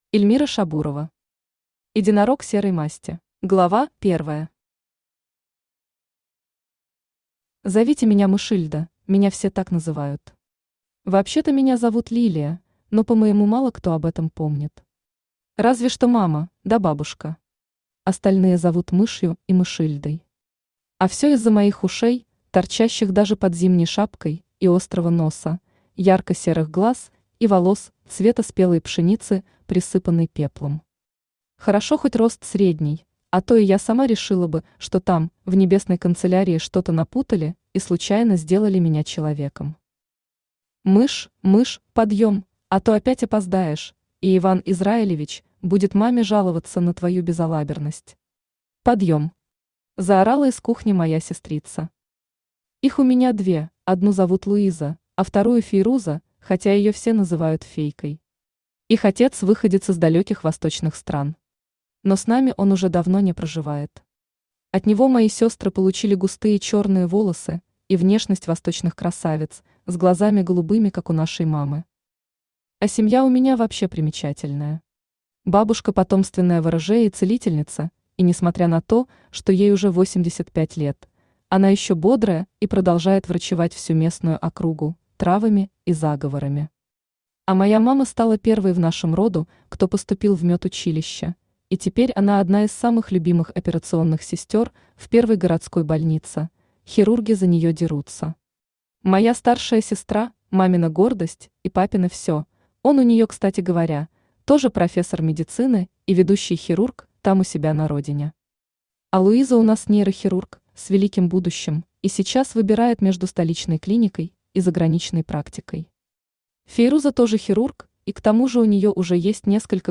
Аудиокнига Единорог серой масти | Библиотека аудиокниг
Aудиокнига Единорог серой масти Автор Эльмира Шабурова Читает аудиокнигу Авточтец ЛитРес.